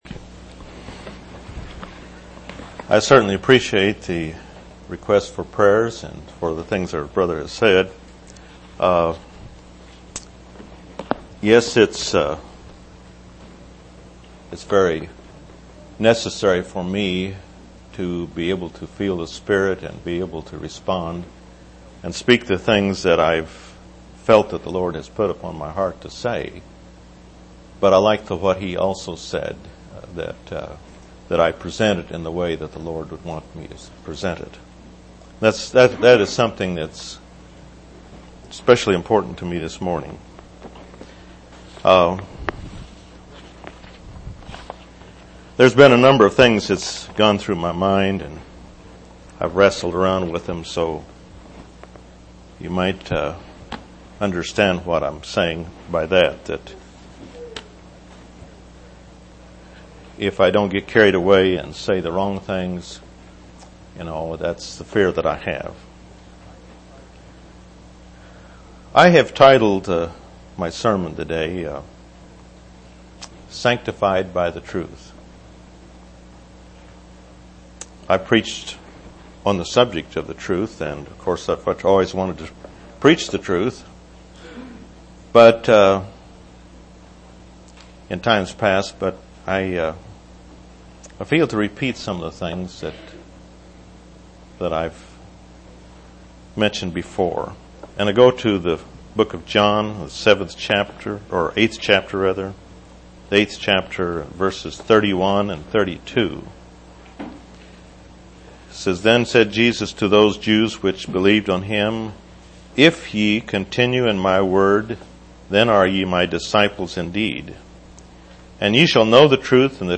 7/11/1999 Location: East Independence Local Event